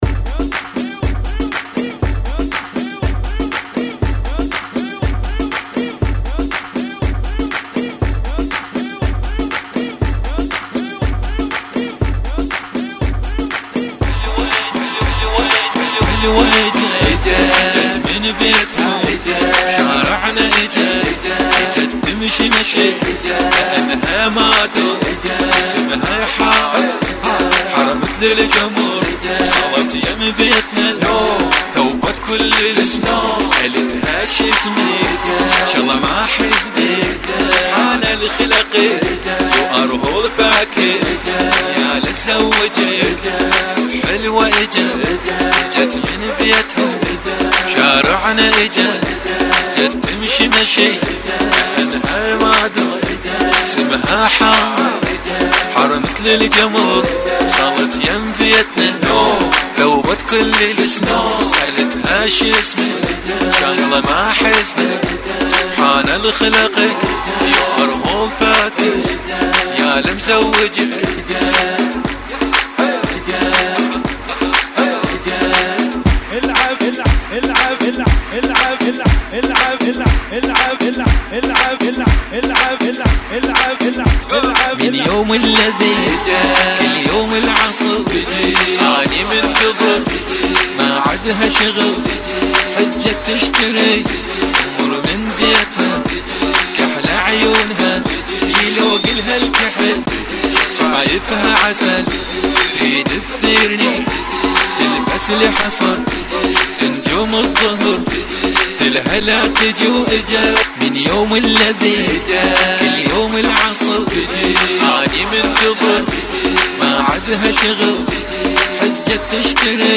[ 120 Bpm ]